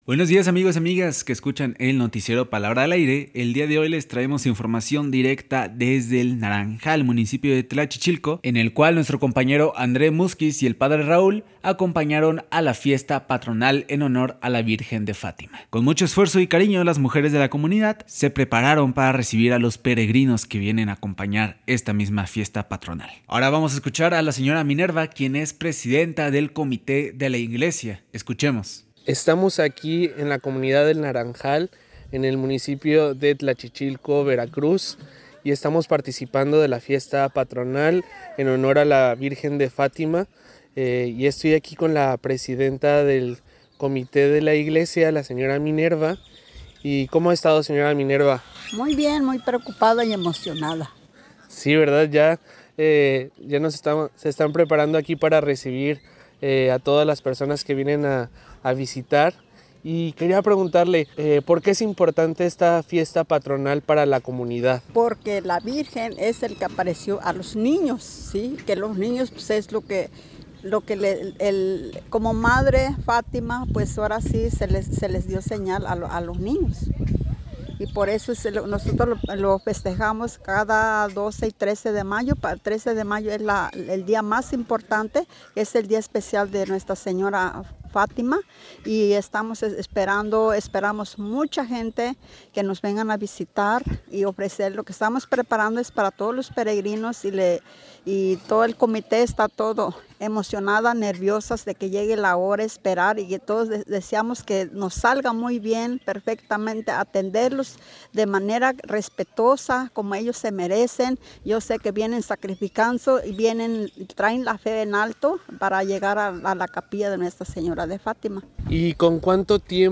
El reporte es